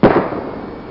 Gunshot Sound Effect
Download a high-quality gunshot sound effect.
gunshot.mp3